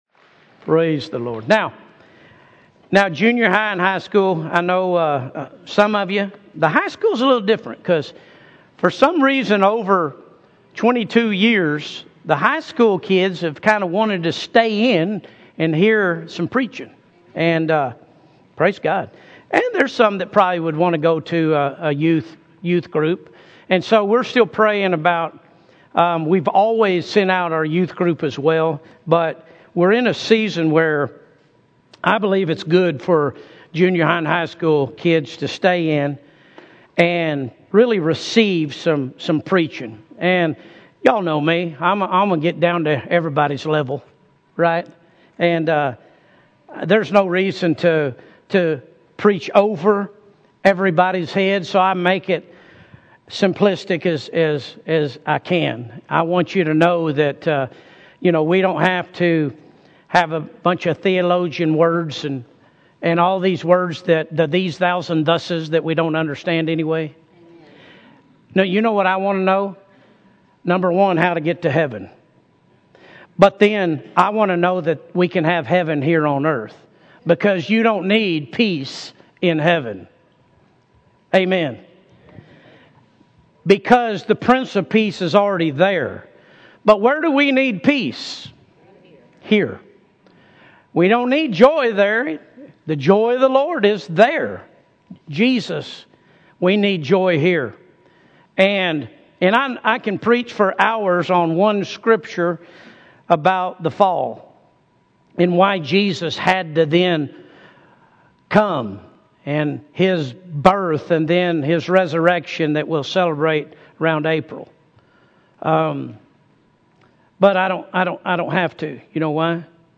Sermons Archive ⋆ Williamson County Cowboy Church - Liberty Hill, TX